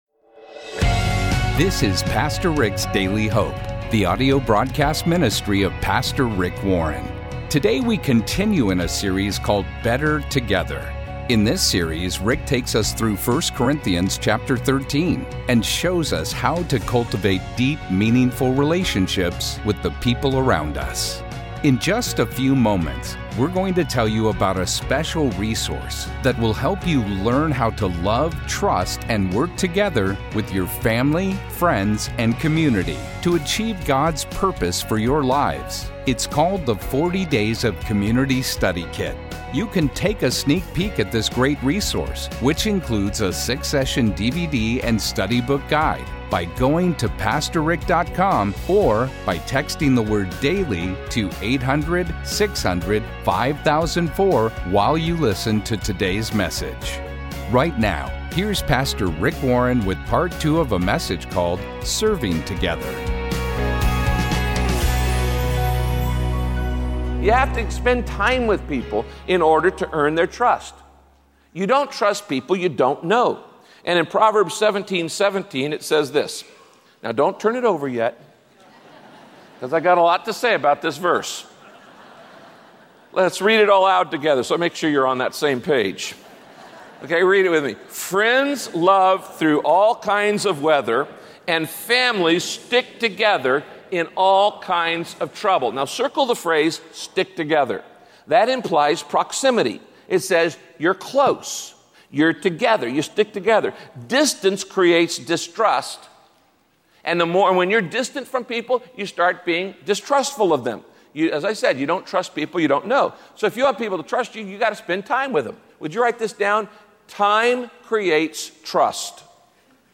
My Sentiment & Notes Serving Together - Part 2 Podcast: Pastor Rick's Daily Hope Published On: Thu Aug 10 2023 Description: Proverbs states that it's hard to find a trustworthy person—and that’s a problem that still exists today. In this message, Pastor Rick teaches how to become trustworthy, which is essential for serving God together with others.